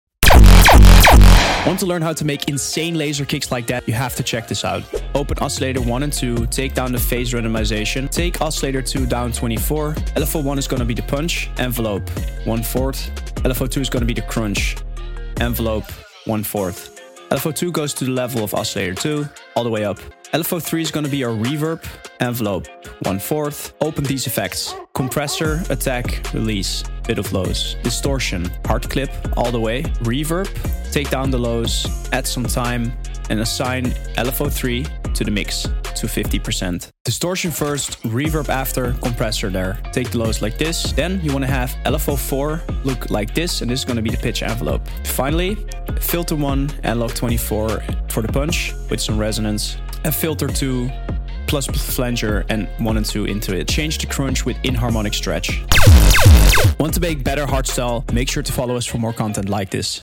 Laser Kicks in Vital? Also sound effects free download